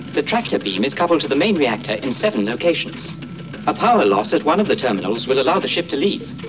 C-3PO TALKING ABOUT TRACTOR BEAM